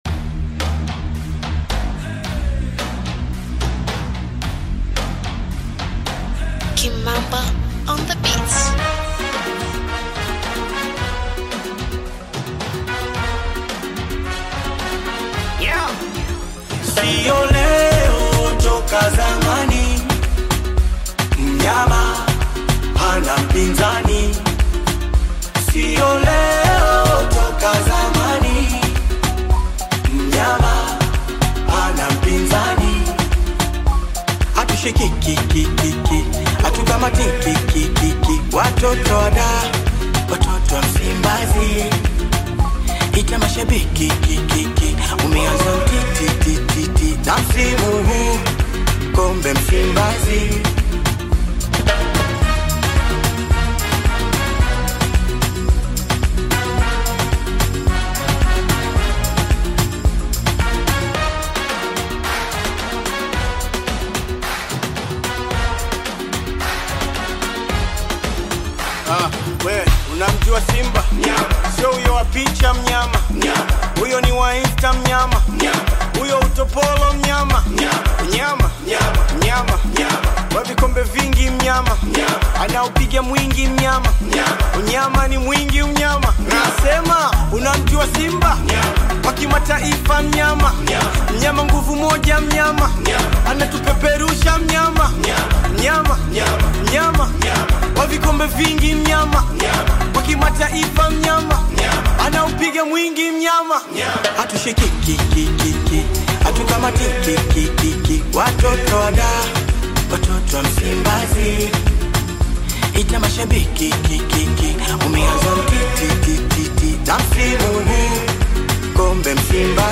Tanzanian independent singer, songwriter and Performer
banger